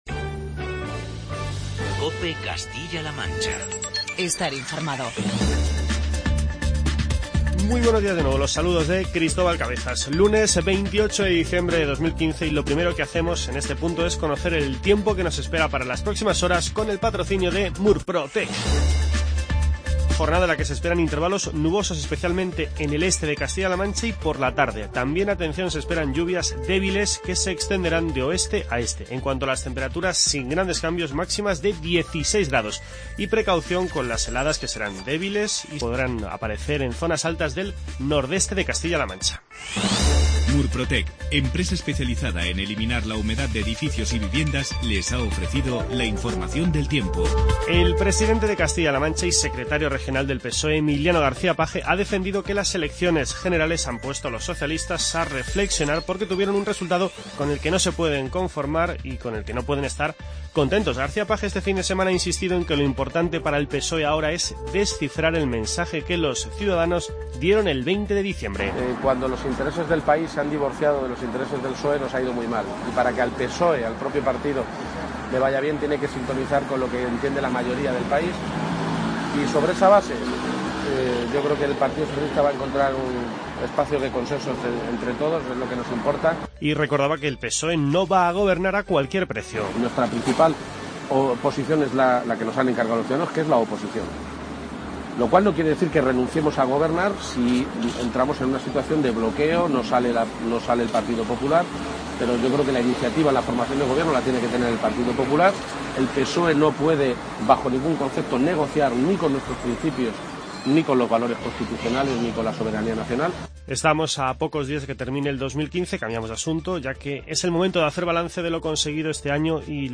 Informativo regional y provincial
Escuchamos las palabras de Emiliano García-Page con respecto a los resultados electorales del 20 de diciembre.